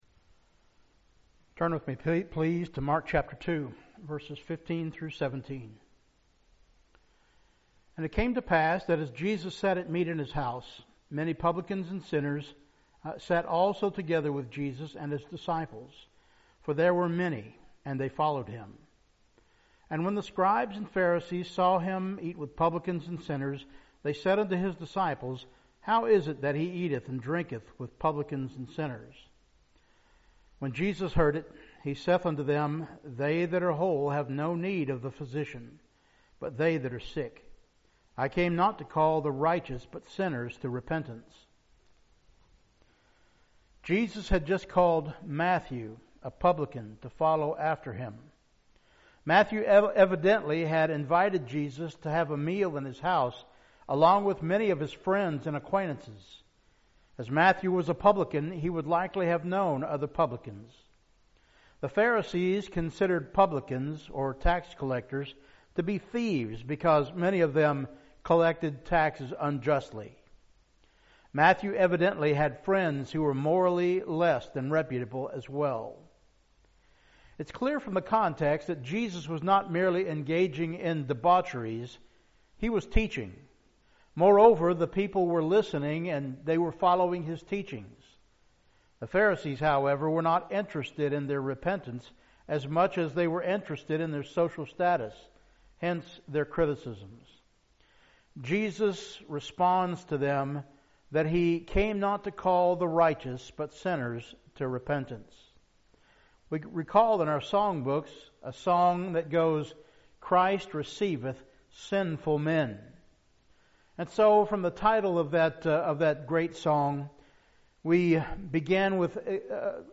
Christ Receiveth Sinful Men – Audio Sermon
christ-receiveth-sinful-men-sermon.mp3